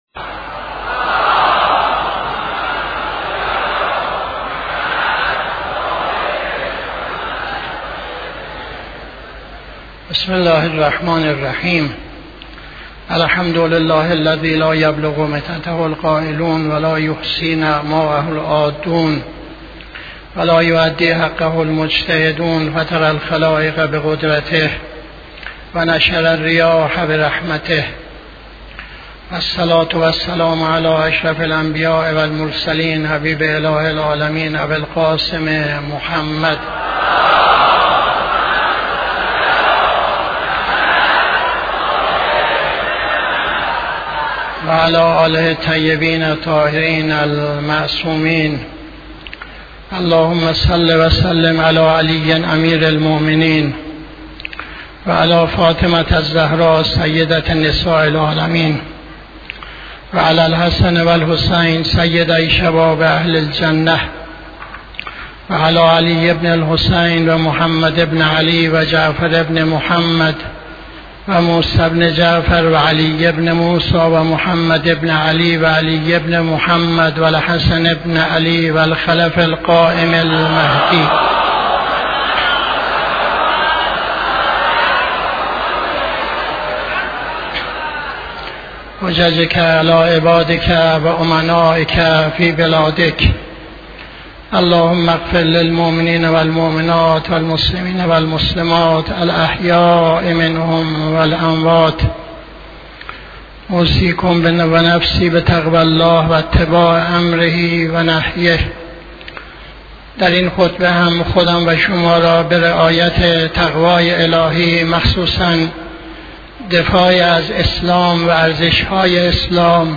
خطبه دوم نماز جمعه 18-03-80